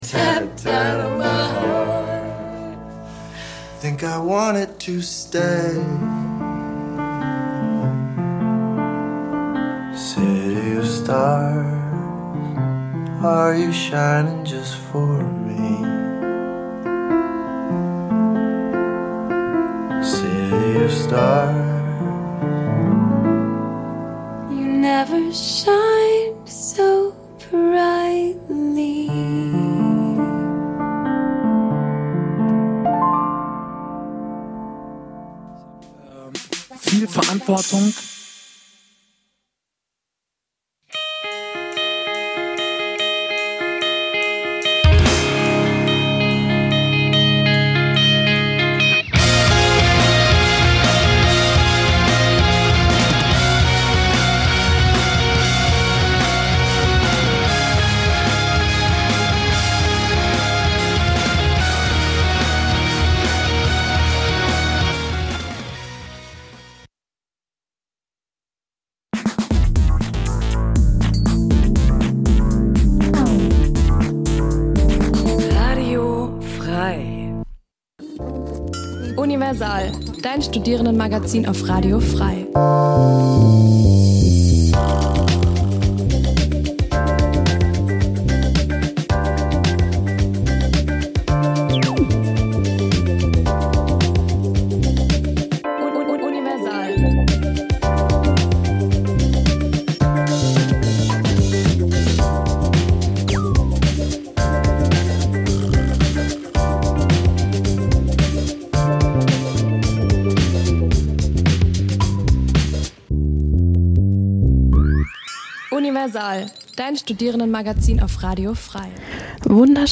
Die Sendungen werden gemeinsam vorbereitet - die Beitr�ge werden live im Studio pr�sentiert.
Studentisches Magazin Dein Browser kann kein HTML5-Audio.